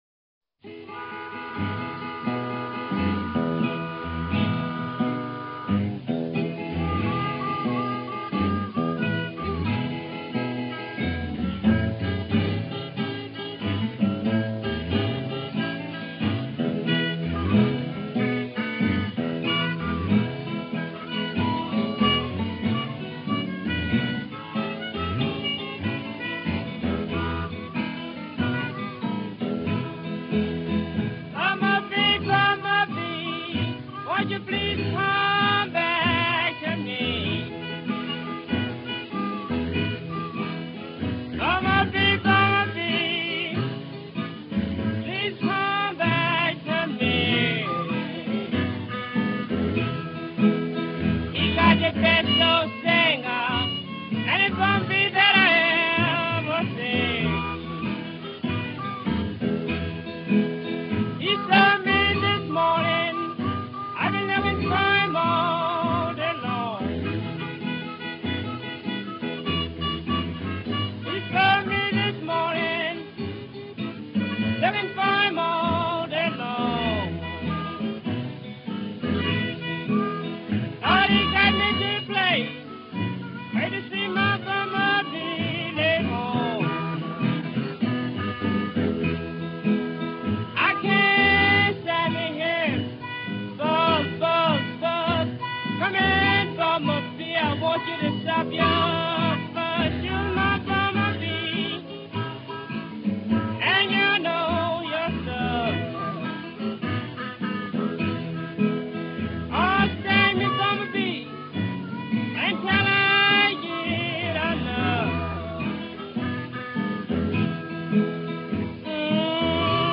guitar, harmonica and Bullfiddle